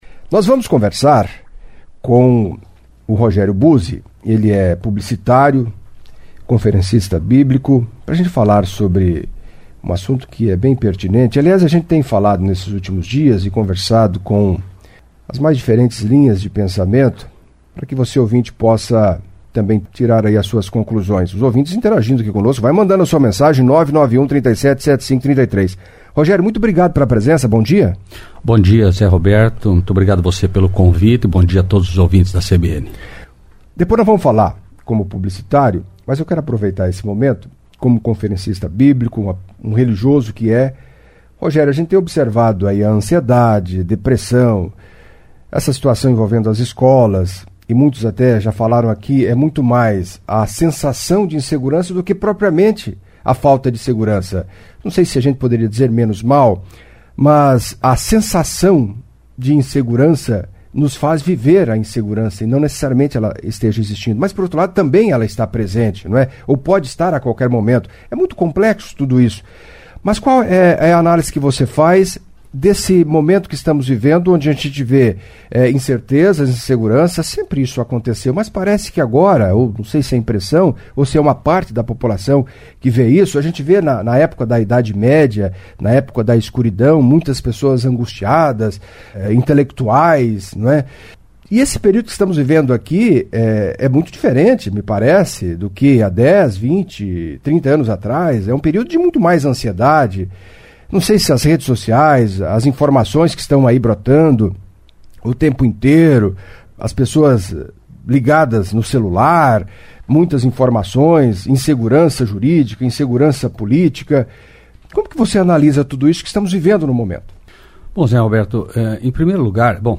Em entrevista à CBN Cascavel nesta terça-feira (25)